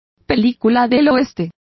Complete with pronunciation of the translation of western.